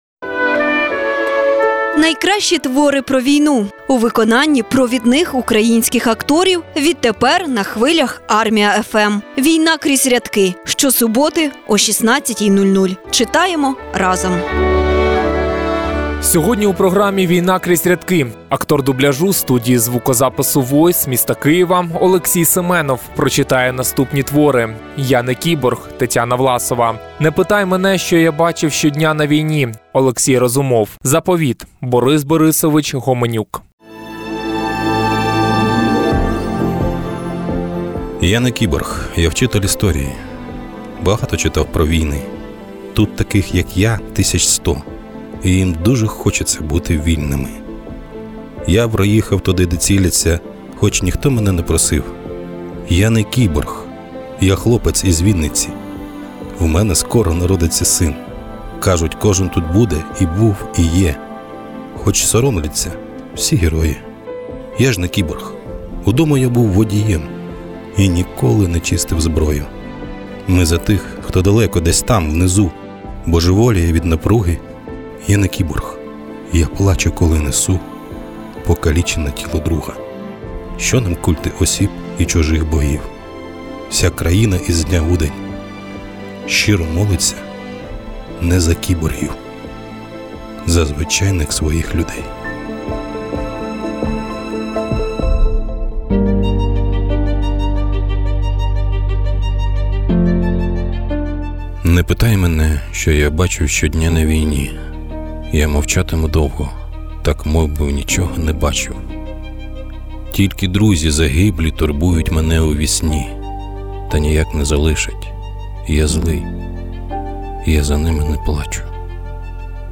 Війна кріз рядки(04.12.21).mp3 (запис на радіо " Армія ФМ"